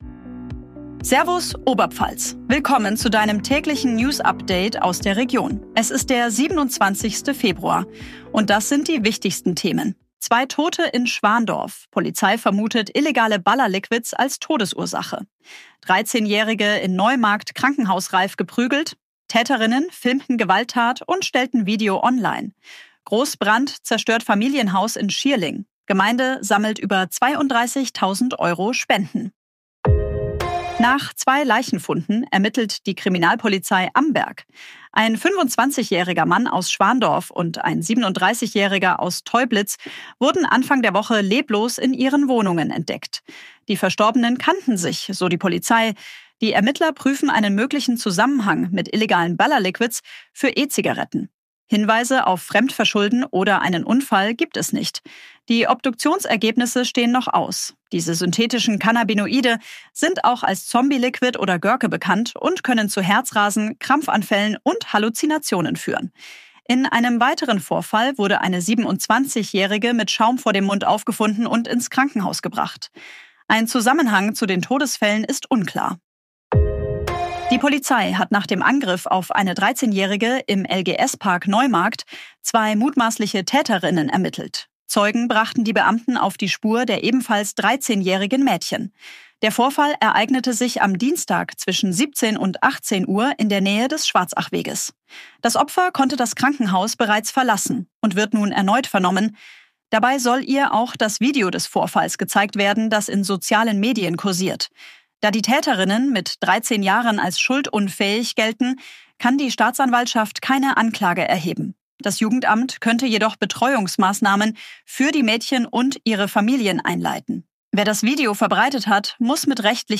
Tägliche Nachrichten aus deiner Region
künstlicher Intelligenz auf Basis von redaktionellen Texten